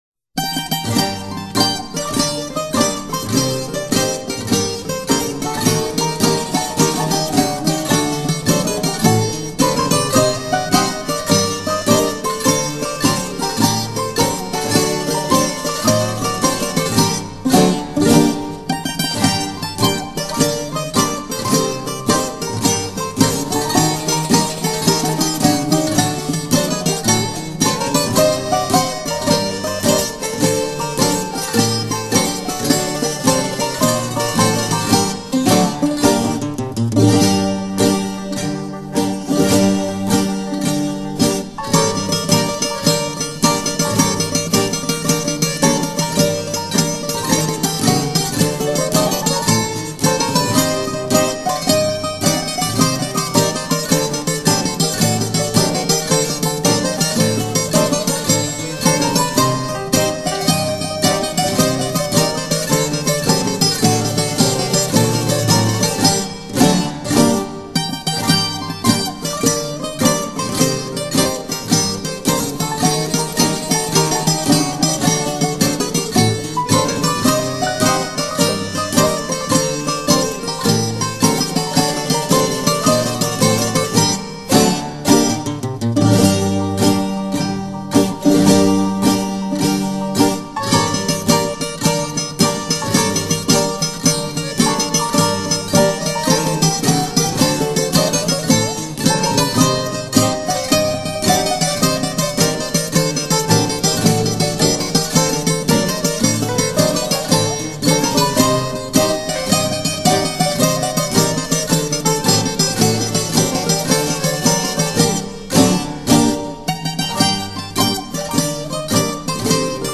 风味浓厚的希腊传统HASAPIKO舞曲